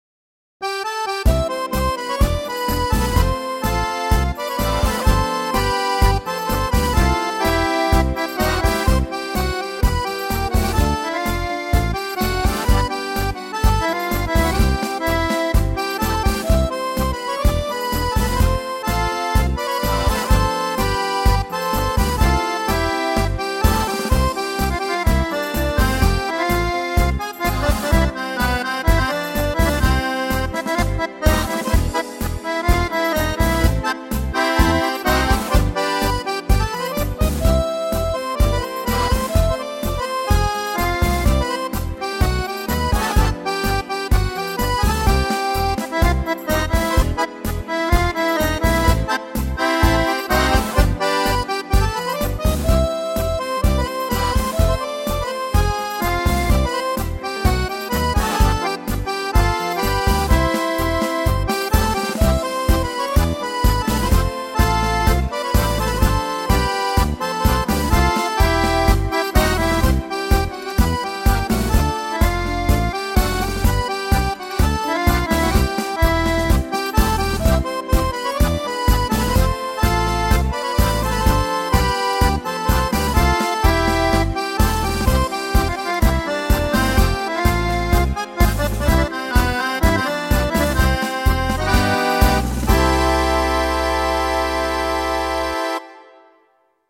Gerne unterhalte ich sie auch instrurmental zum Essen auf meinem Keyboard.
Auf dieser Seite finden sie auch ein paar Demos, bzw. Liveaufzeichnungen die ich selbst erstellt habe. Diese wurden also nicht im Tonstudio abgemischt und aufgemöbelt.